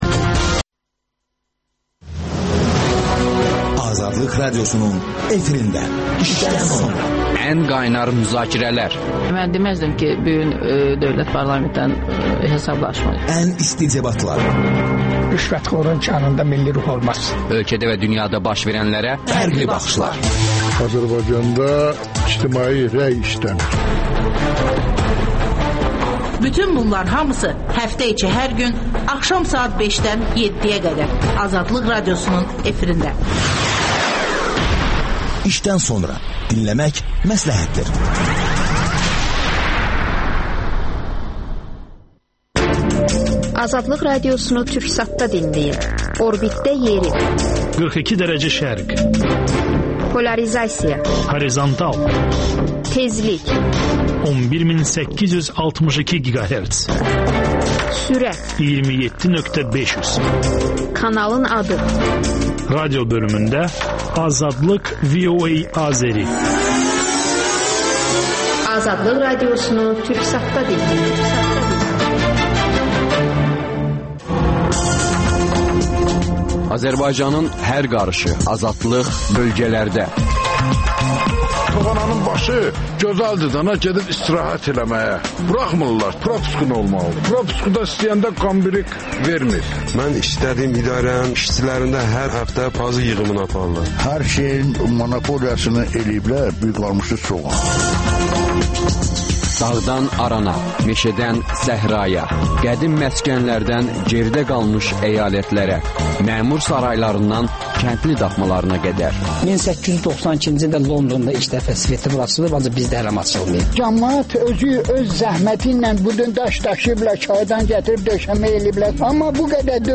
İranın Azərbaycandakı keçmiş səfiri Əfşar Süleymanı bu haqda danışır.